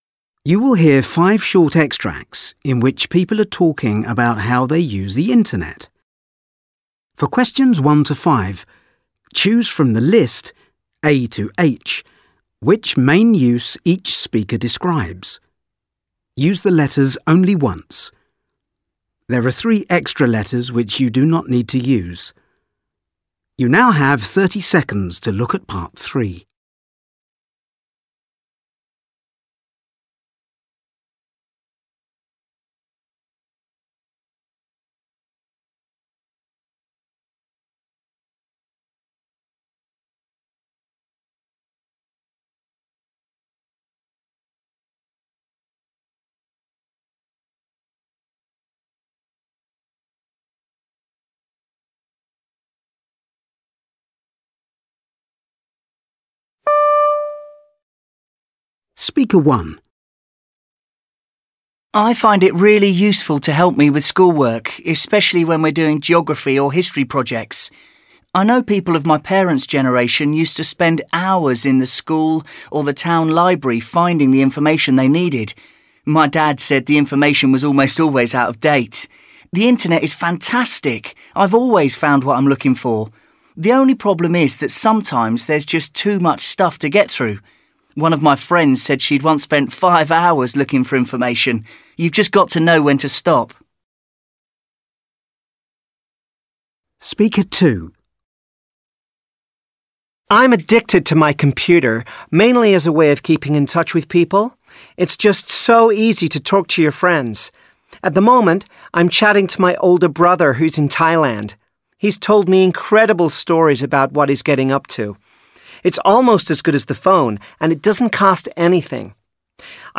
You will hear five short extracts in which people are talking about how they use the Internet.